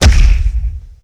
HeavyHit1.wav